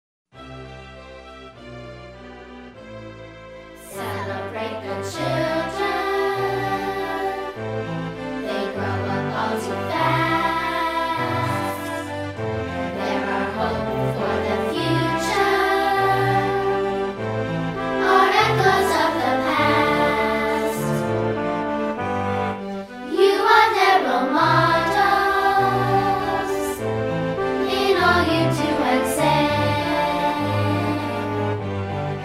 ▪ The full-length music track with vocals.
Listen to a sample of this song.